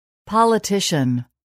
politician.mp3